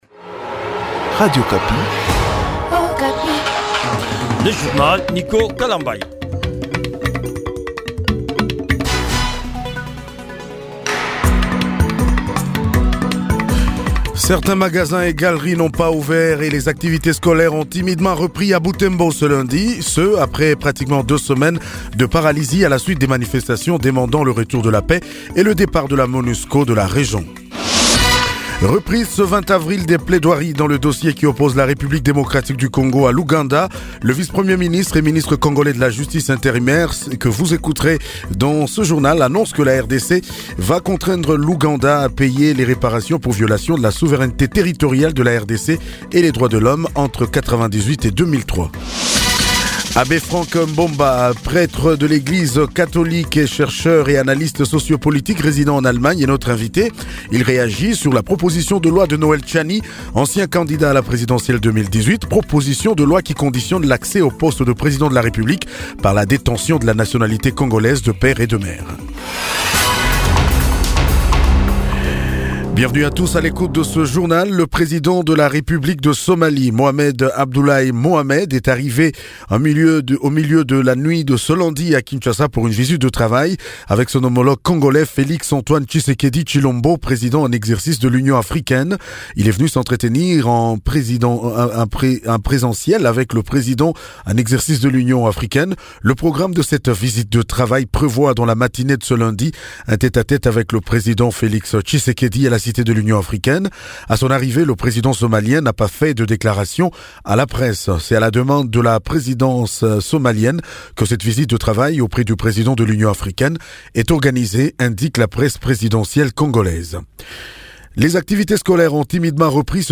JOURNAL MIDI DU LUNDI 19 AVRIL 2021